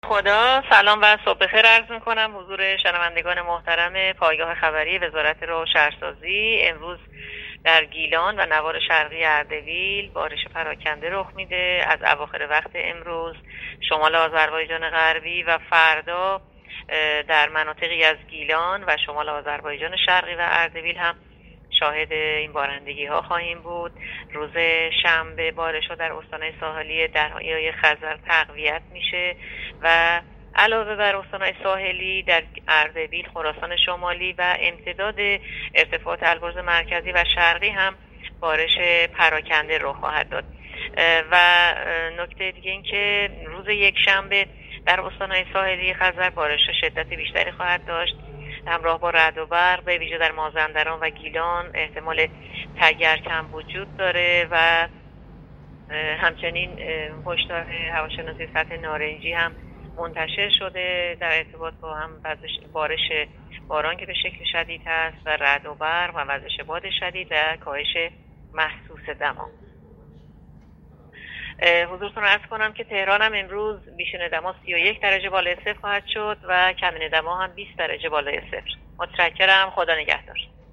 گزارش رادیو اینترنتی پایگاه‌ خبری از آخرین وضعیت آب‌وهوای ۲۷ شهریور؛